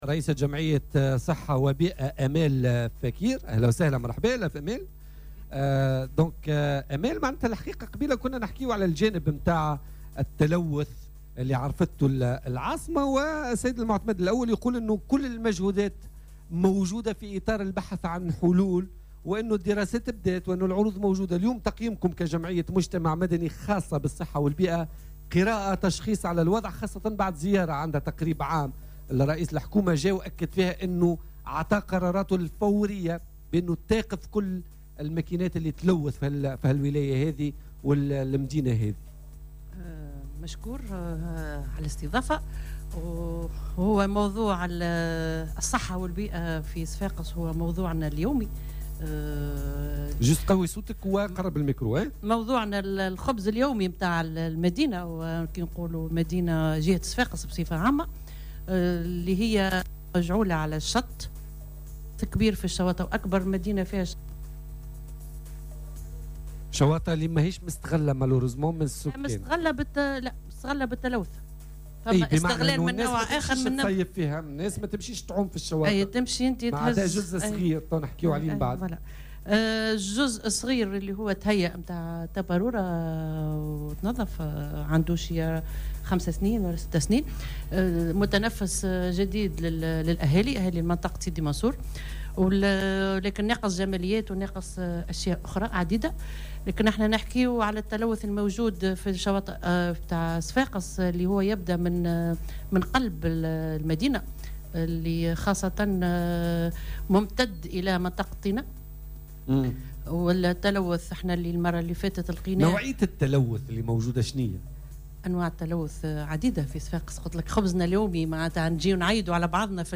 أجمع ضيوف "بوليتيكا" التي بثت اليوم الخميس في حلقة خاصة من ولاية صفاقس، على الوضع المتدهور في الولاية في مختلف القطاعات، الصحية والاجتماعية والتنموية وخاصة معضلة التلوث.